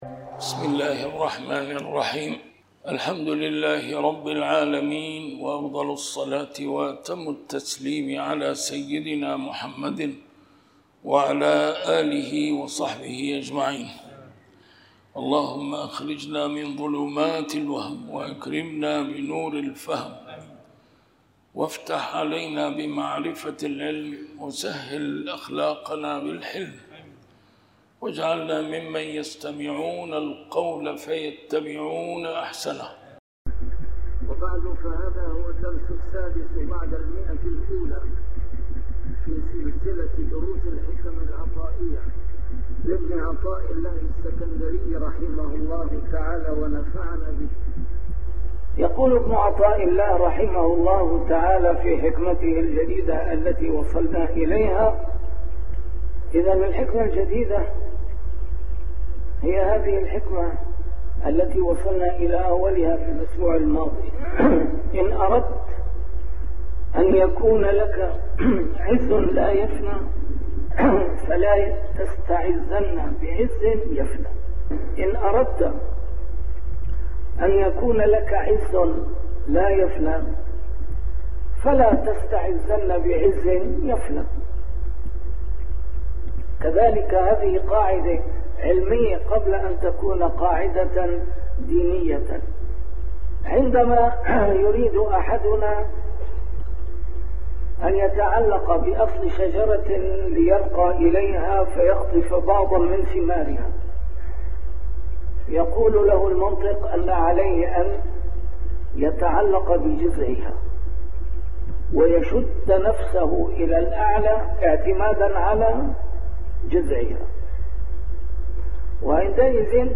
الدرس رقم 106 شرح الحكمة 86